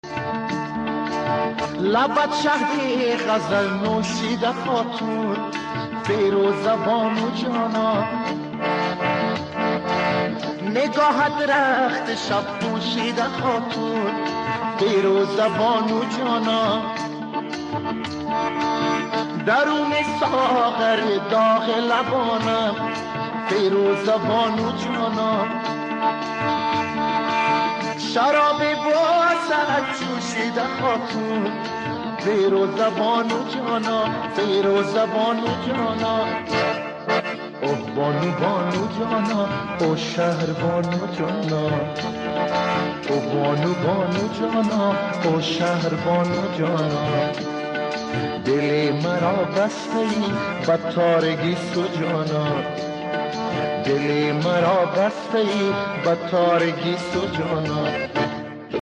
AI Music Revival